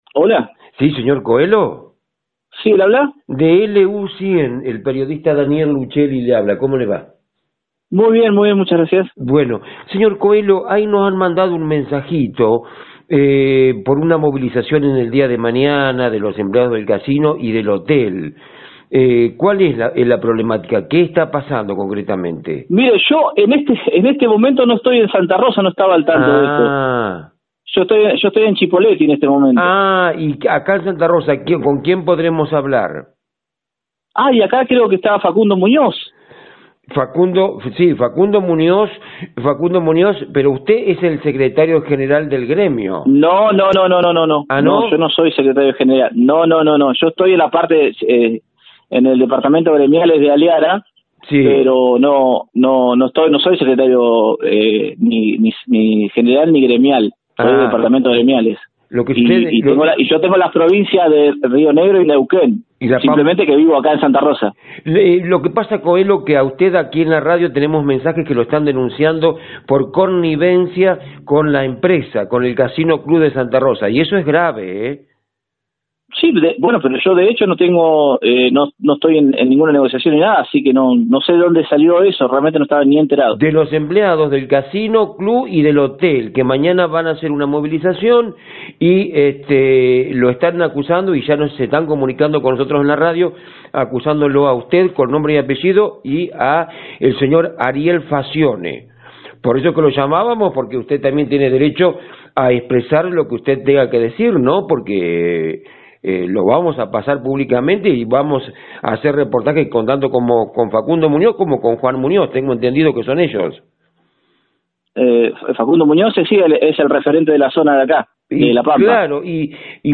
AUDIO | Protesta de trabajadores del Casino: "no estoy al tanto de lo que está pasando", sostuvo referente del gremio - Diarionoticias - La información de Primera